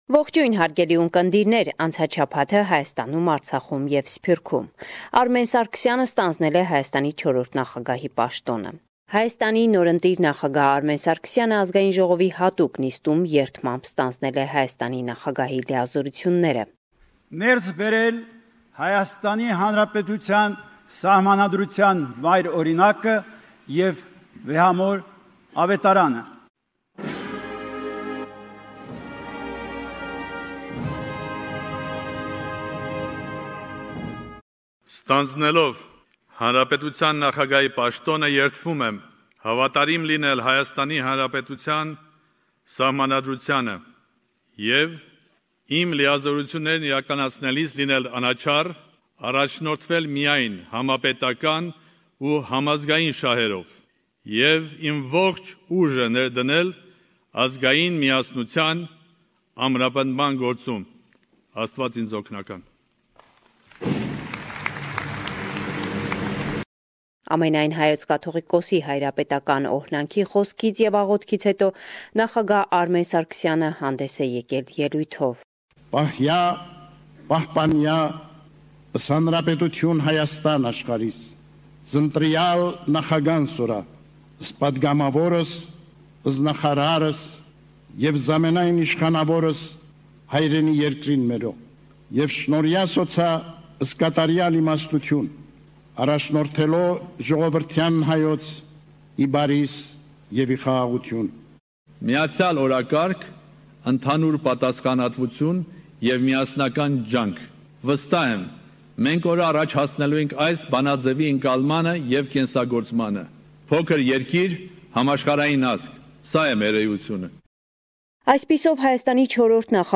Վերջին Լուրերը – 10 Ապրիլ 2018